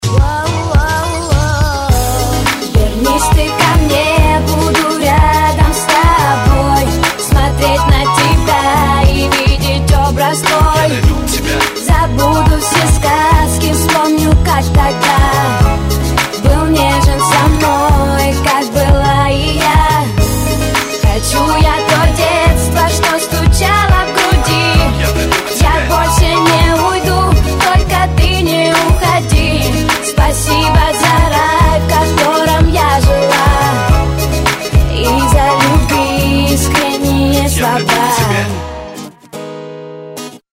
Rap, RnB, Hip-Hop